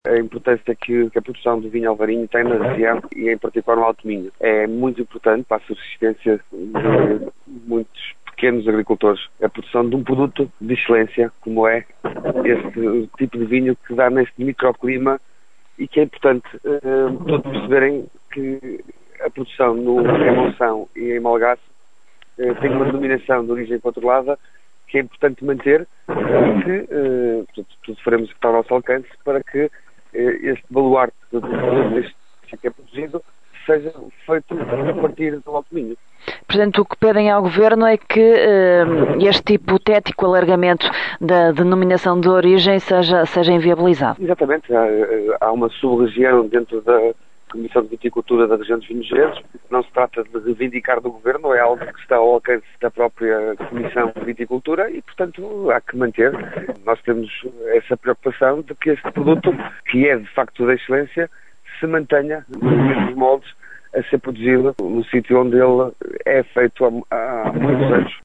O deputado do PSD, Eduardo Teixeira, explicou à Rádio Caminha a razão pela qual este projecto de resolução foi agora apresentado: